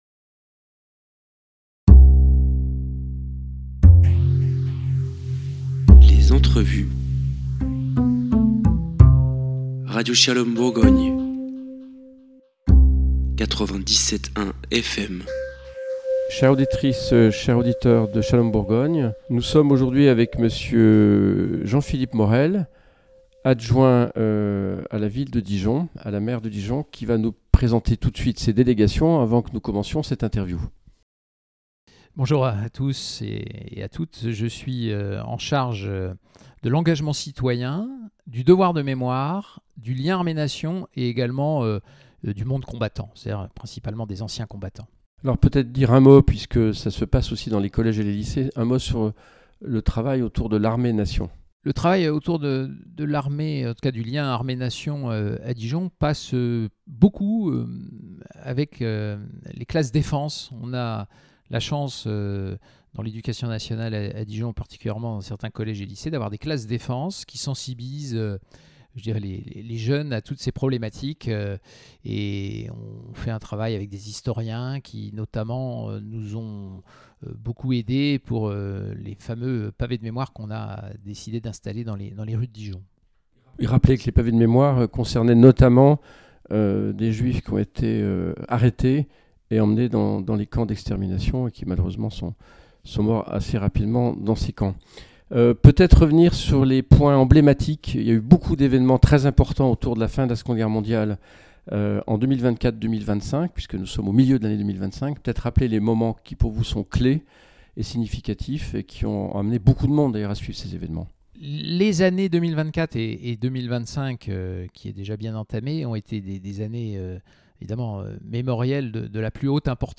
Et la nécessité de reprendre dans la rigueur et le dialogue la question de la laïcité. Entretien